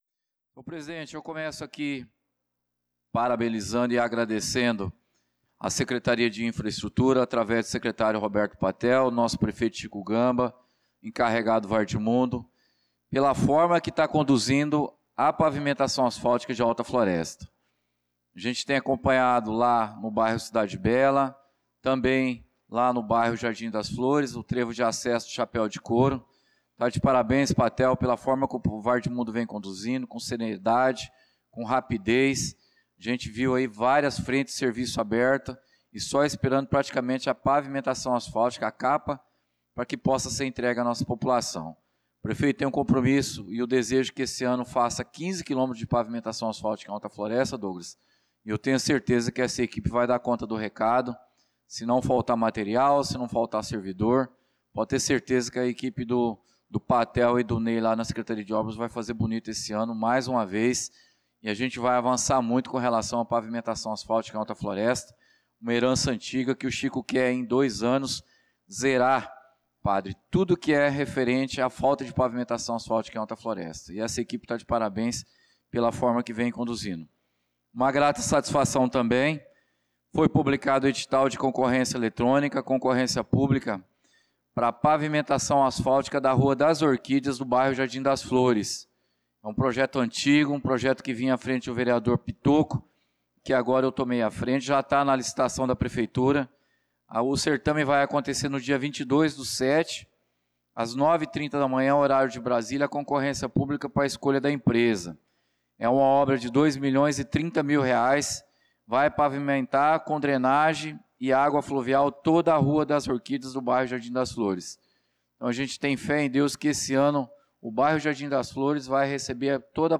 Pronunciamento do vereador Claudinei de Jesus na Sessão Ordinária do dia 16/06/2025.